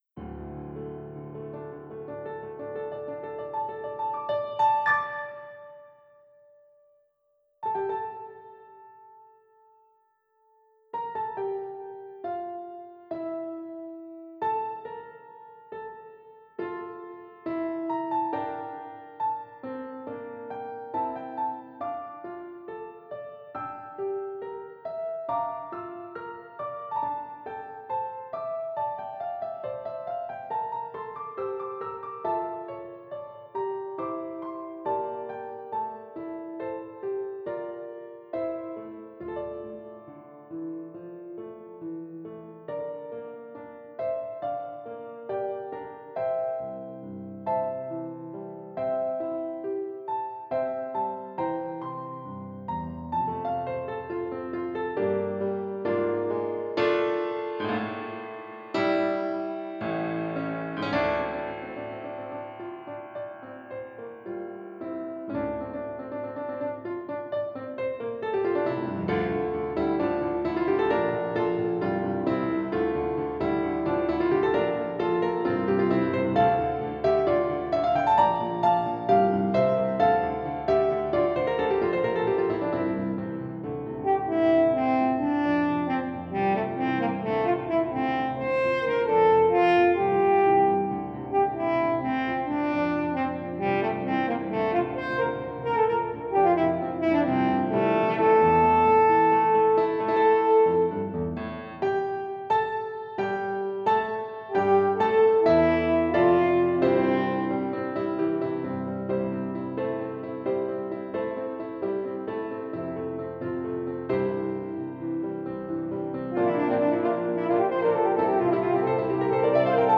for French Horn and Piano